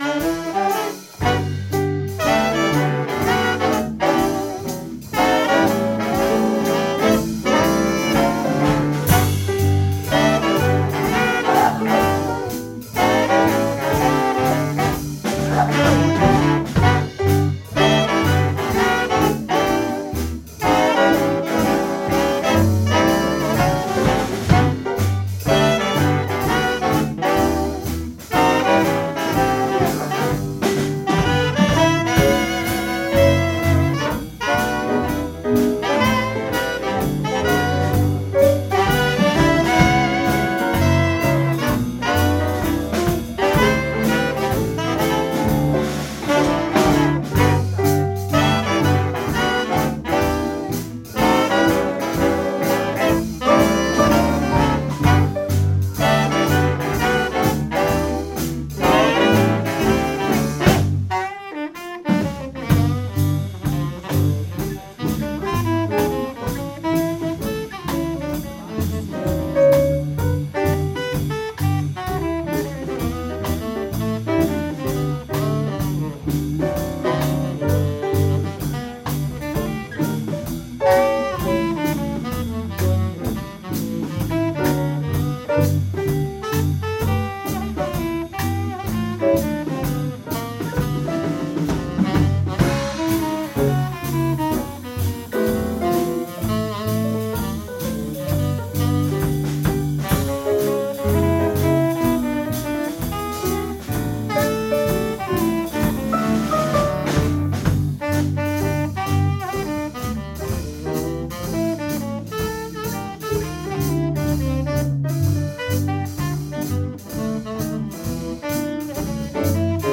Jazz-nonetten på Lautrupgaard - optagelser 2025
baritonsax
altsax og sopransax
trommer
piano
guitar
Lyd i mp3-format, ca. 192 kbps, optaget stereo - ikke studieoptagelser!.